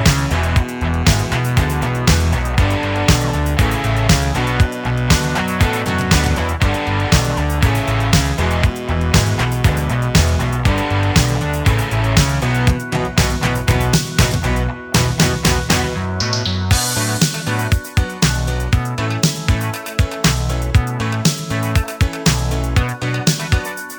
no Backing Vocals or FX Disco 4:14 Buy £1.50